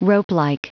Prononciation du mot ropelike en anglais (fichier audio)
Prononciation du mot : ropelike